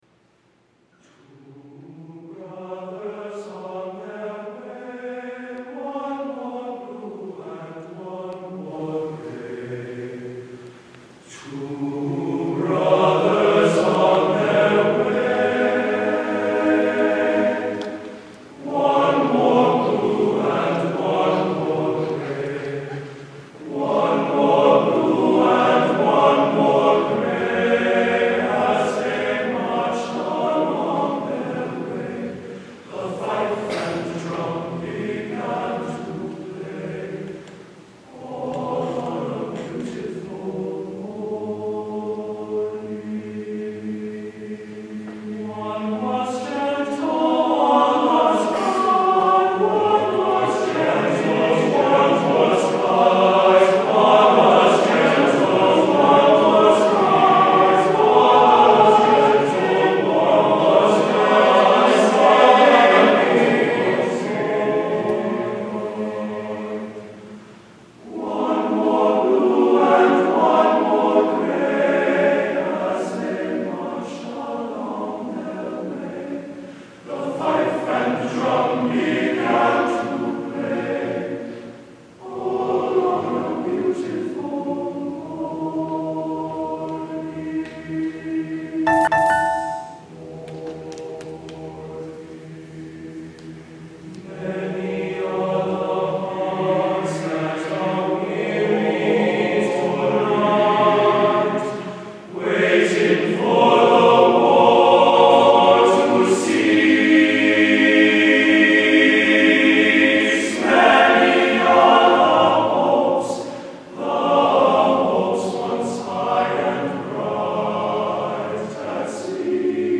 at St Thomas Aquinas Church 2012